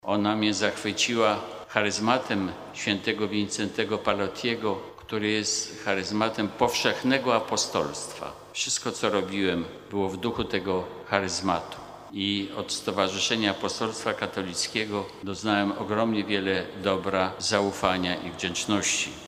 Z tej okazji w katedrze świętego Michała Archanioła i świętego Floriana Męczennika odprawiona została uroczysta msza święta z udziałem biskupów metropolii warszawskiej, na czele z kardynałem Kazimierzem Nyczem i abp. Tadeuszem Wojdą.
Dziękując wszystkim za modlitwę i współpracę na różnych polach duszpasterskiego zaangażowania, abp Hoser podkreślił, że starał się zawsze odpowiadać na wolę Boga.